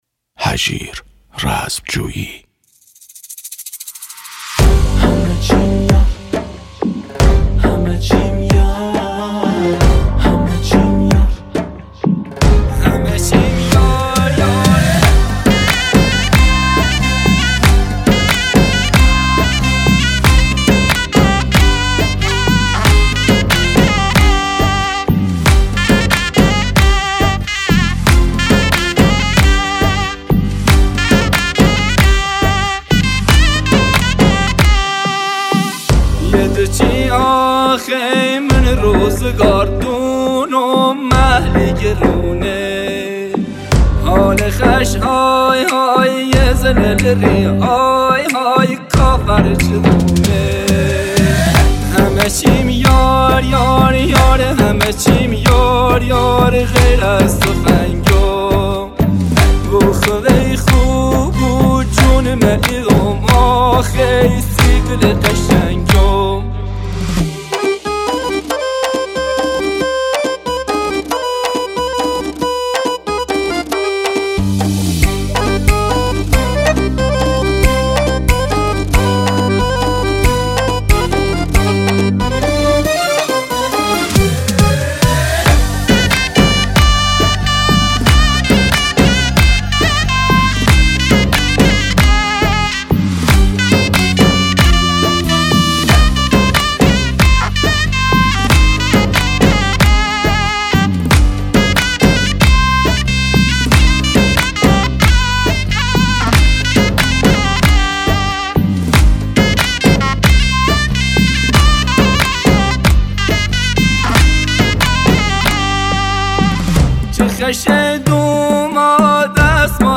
ترانه محلی